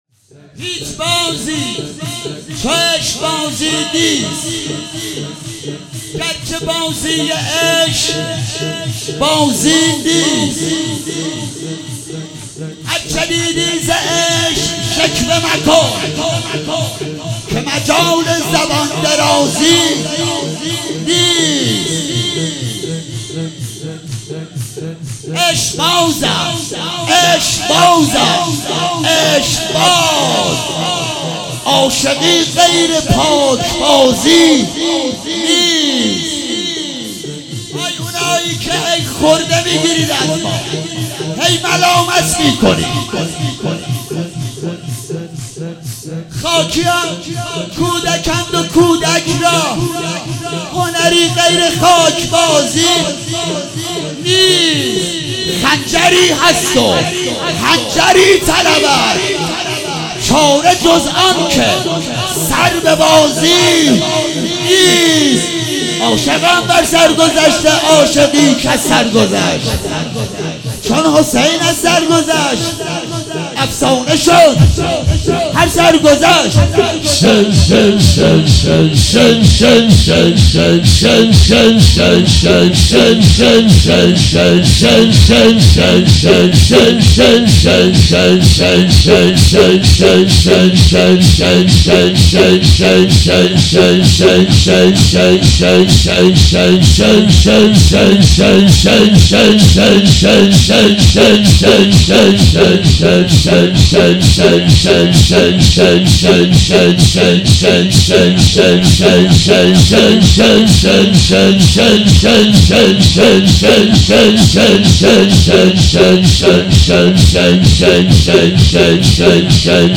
شب 29 صفر 96 - رجز و ذکر